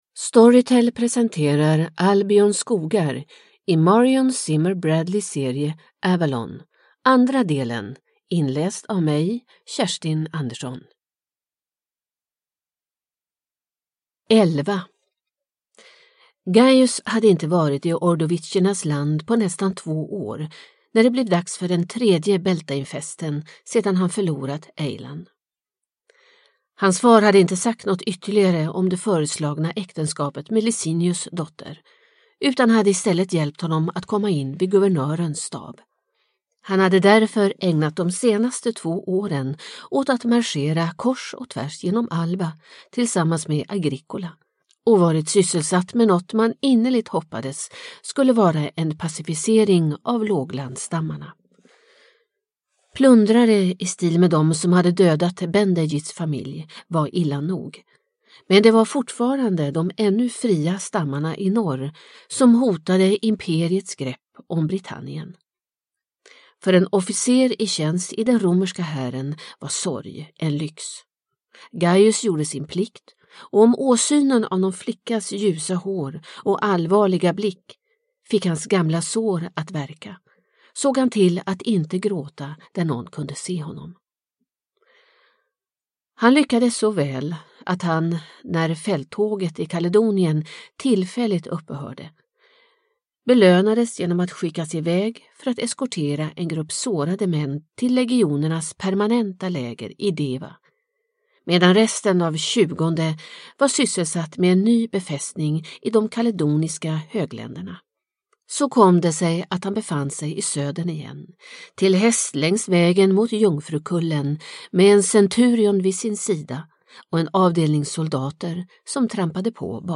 Albions skogar – del 2 – Ljudbok – Laddas ner